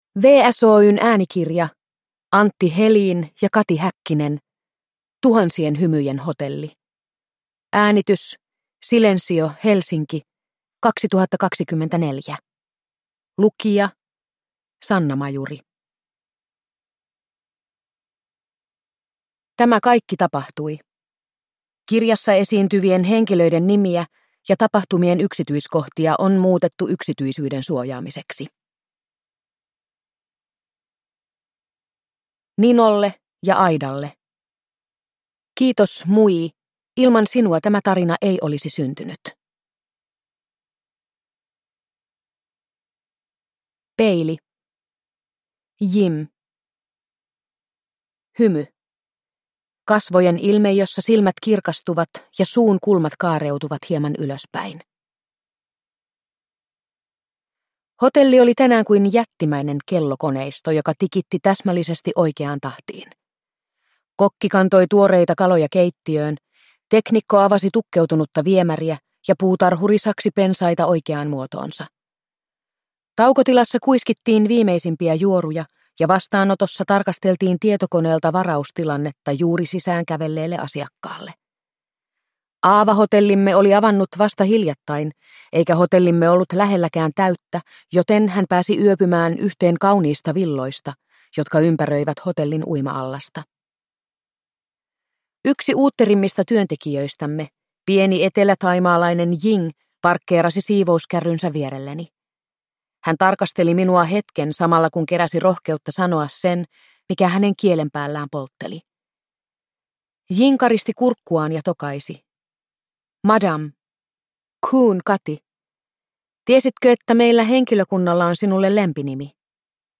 Tuhansien hymyjen hotelli – Ljudbok